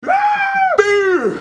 Play, download and share HÃAAAAAAA PIU original sound button!!!!